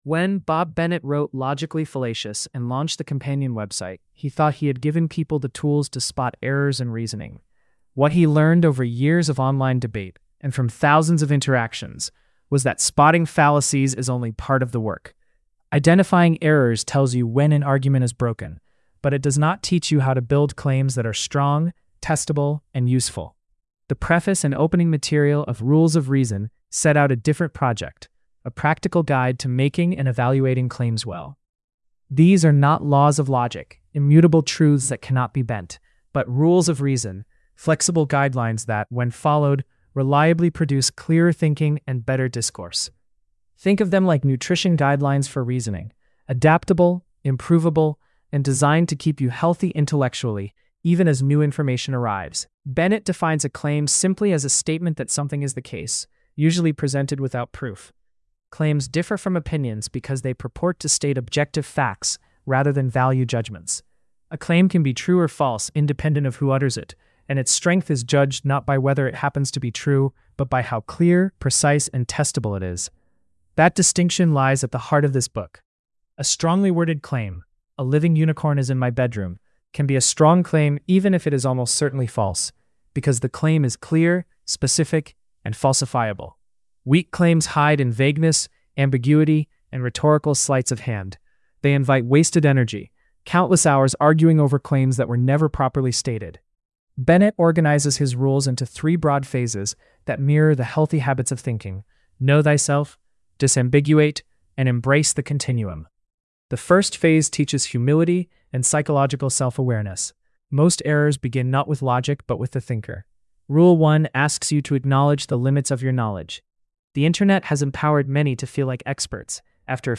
Read or listen to the AI-generated summary of